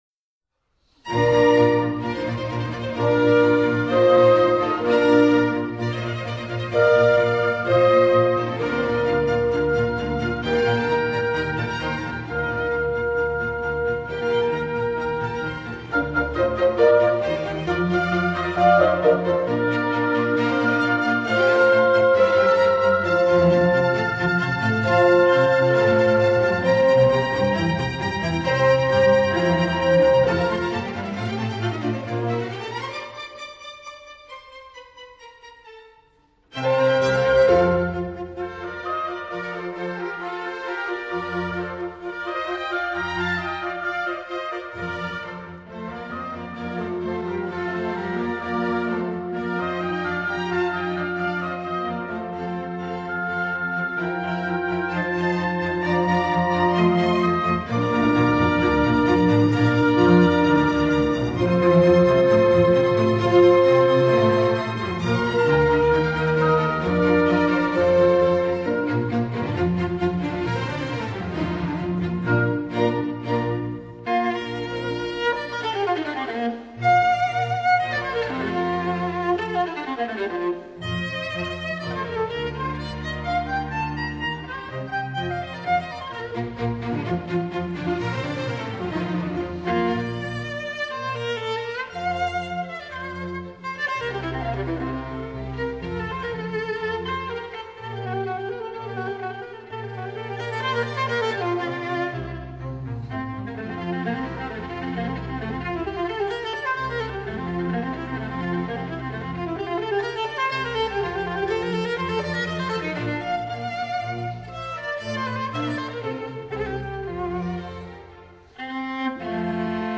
Viola Concerto in B flat major